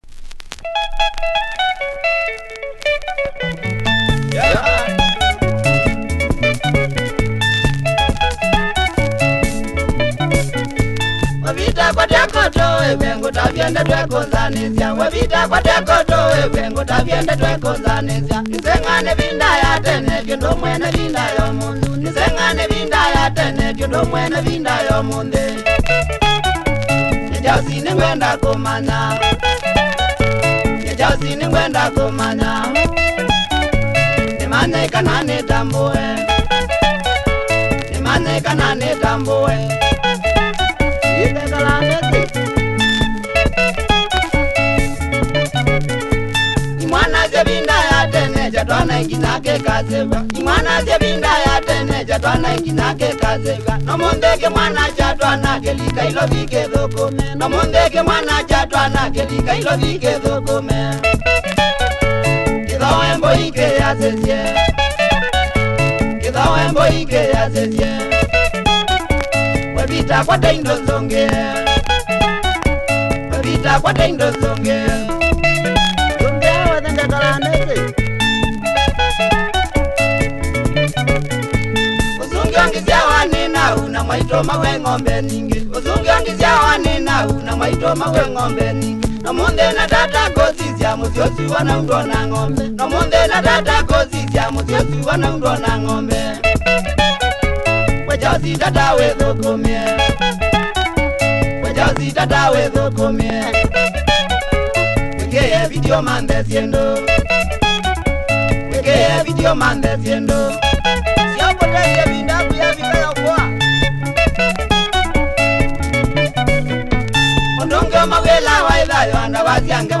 Nice Kamba benga, check audio of both sides! https